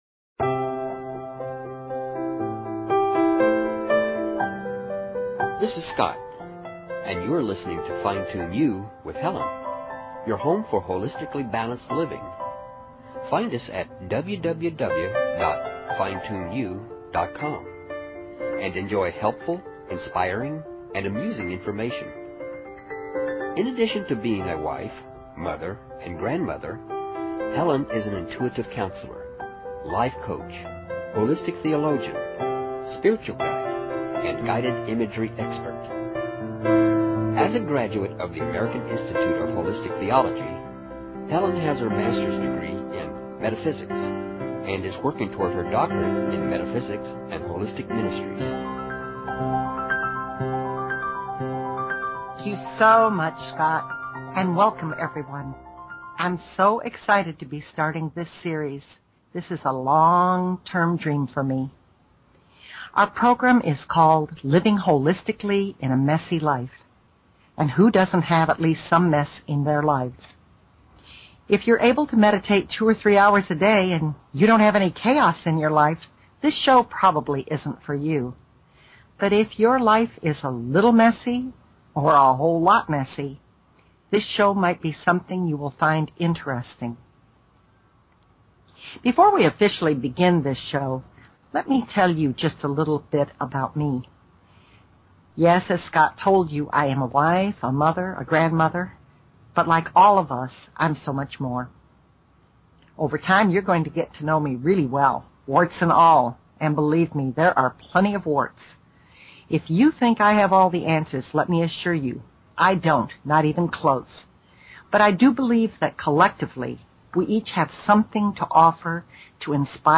Talk Show Episode, Audio Podcast, Fine_Tune_You and Courtesy of BBS Radio on , show guests , about , categorized as